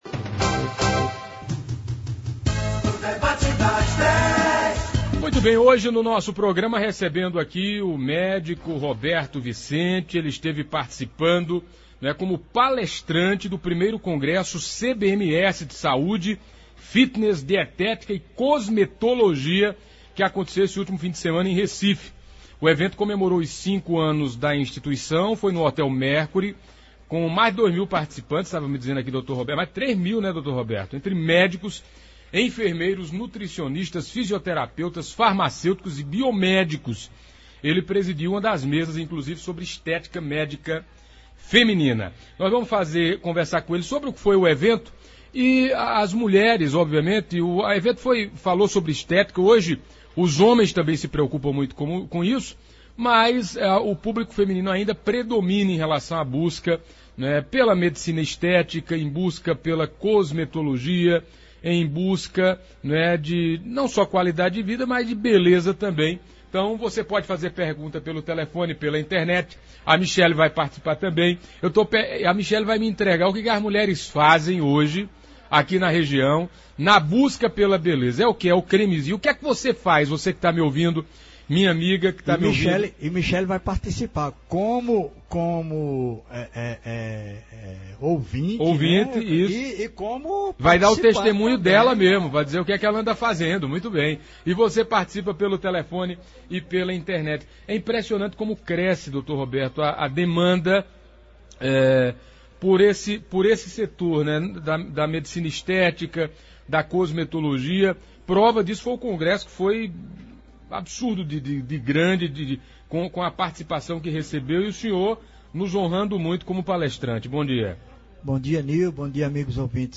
Ouça abaixo na íntegra como foi o debate de hoje: